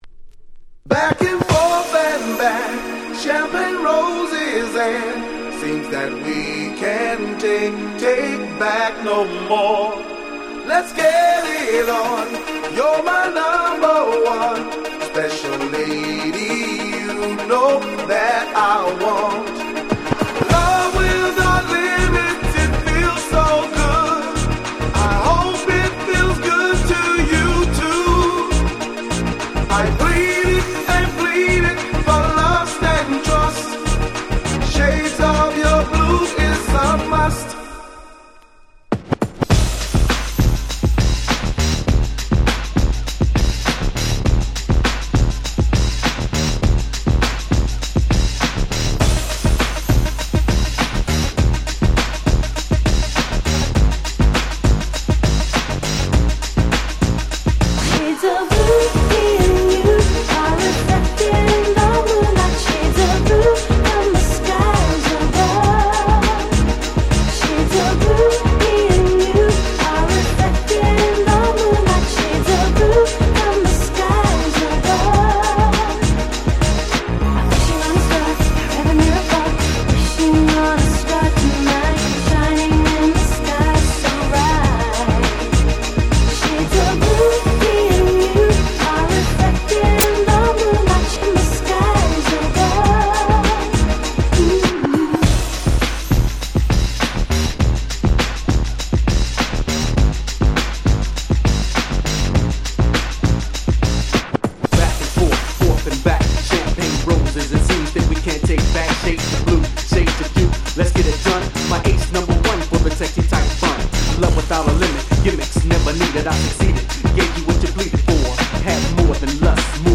97' Nice Euro G-Rap !!
彼らの他の楽曲を聴くと、本作ももっともっとキャッチーな作風かと思いきやこちらは割と重た目な内容。
でもサビでは女性Vocalが入って来たりで持ち前のキャッチーさは健在。
フレッシュアンドファンキー 90's Euro-G キャッチー系 R&B